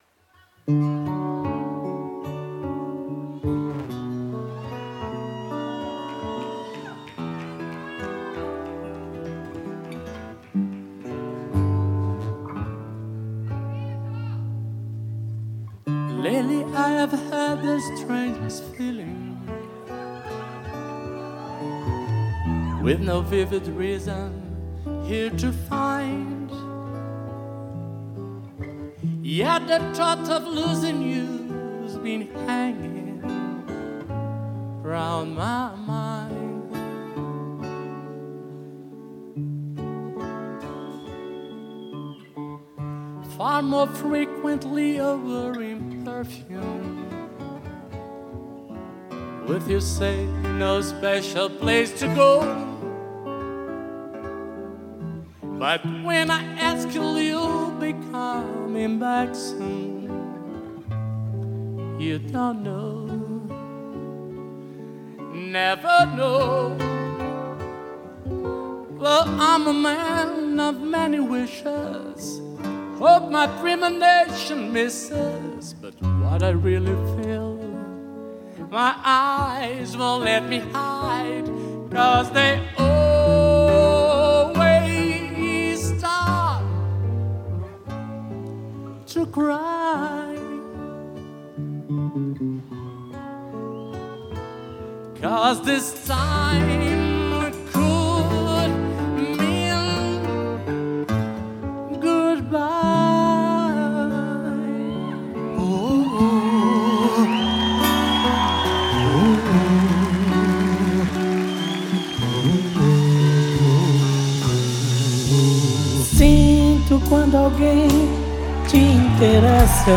(Ao vivo)